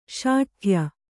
♪ śaṭhya